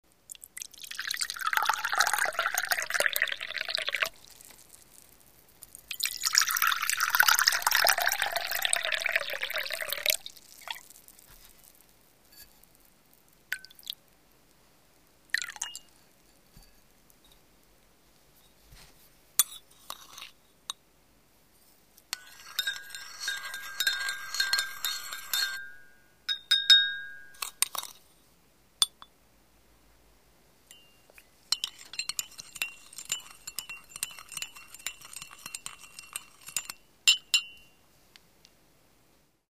Звуки чашки
Звук наливания чая в чашку и последующего размешивания сахара ложкой